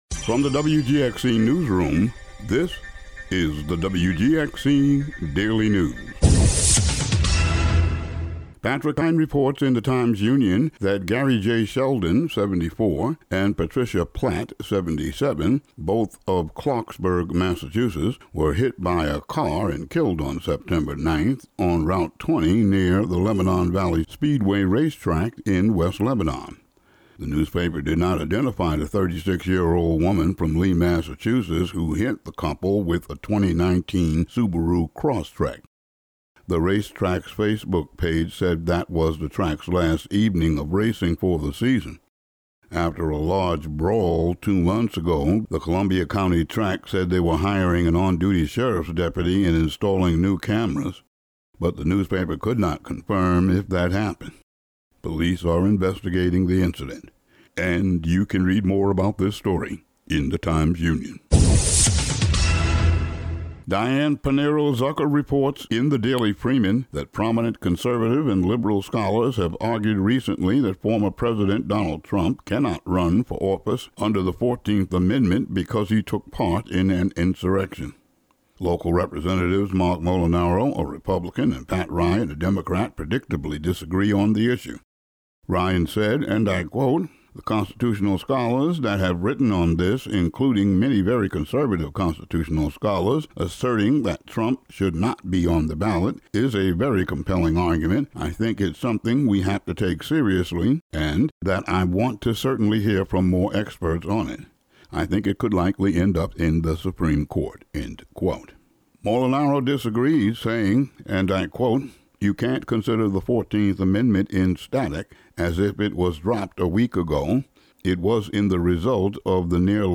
Today's daily news audio update.
Today's daily local audio news.